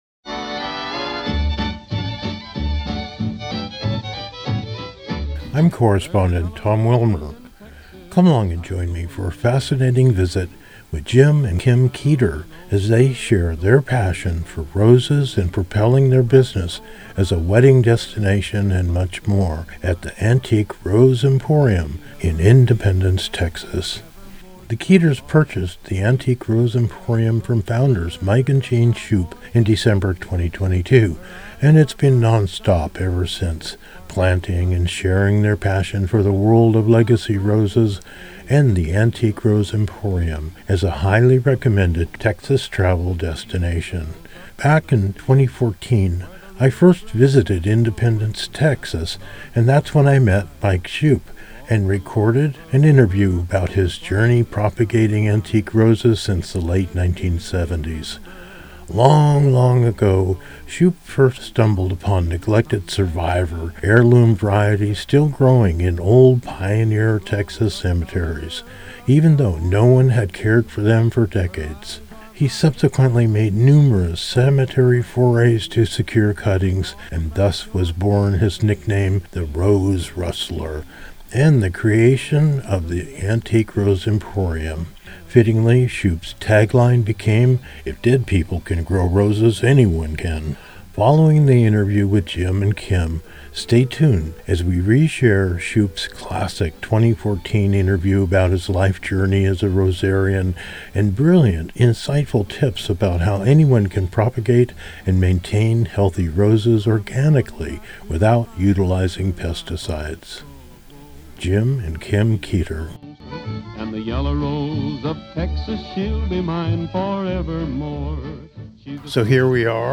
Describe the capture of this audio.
recorded live on location across America and around the world